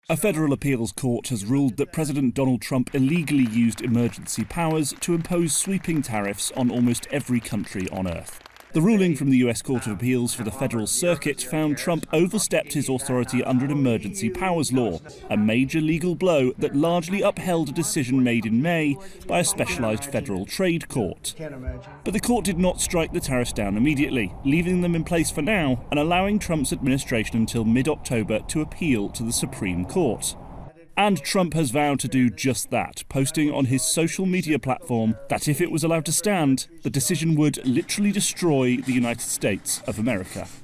reports on a new legal ruling on President Trump's sweeping actions on tariffs.